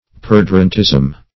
perdurantism - definition of perdurantism - synonyms, pronunciation, spelling from Free Dictionary
perdurantism \per*dur"ant*ism\ (p[~e]r*d[=u]r"ant*[i^]z'm), n.